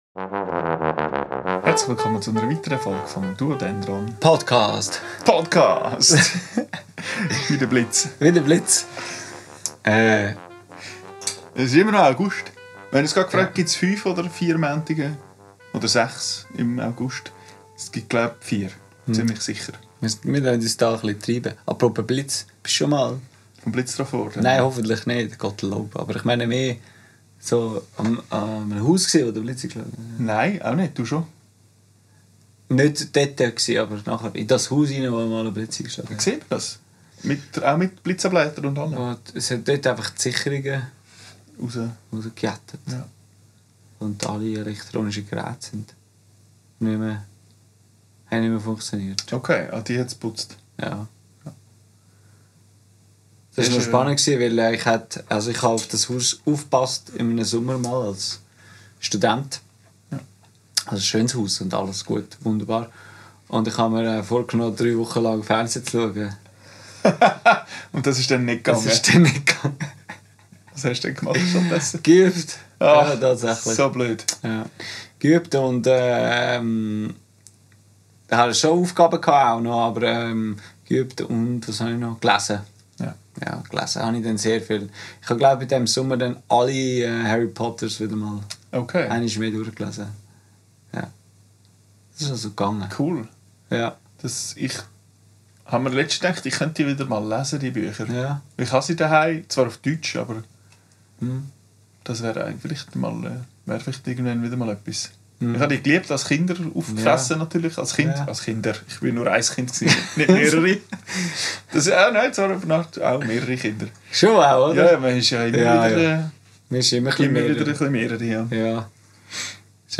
Aber nach einer ausgedehnten Unterhaltung über das Zauberei-Universum spielen wir auch wieder eine freie Improvisation.